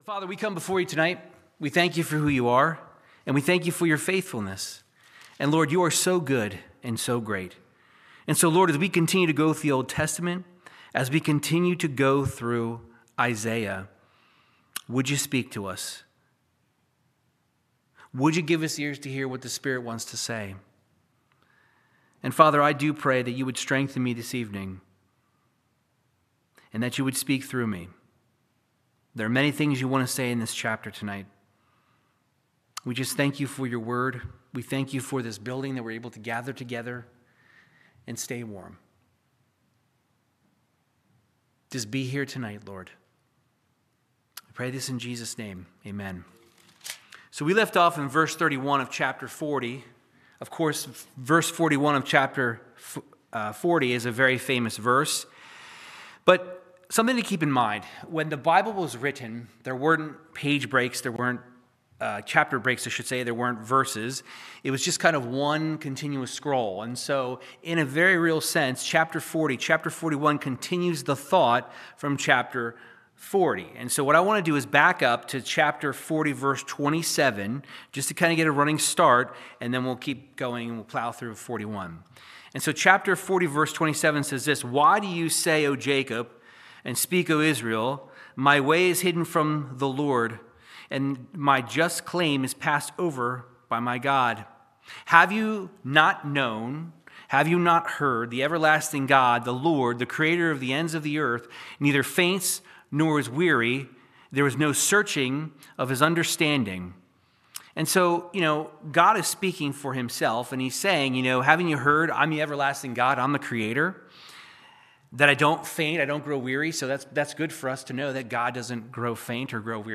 Verse by verse Bible teaching through the book of Isaiah chapters 40 and 41